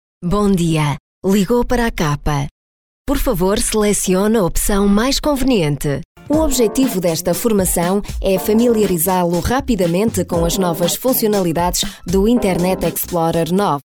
Portuguese – female – AK Studio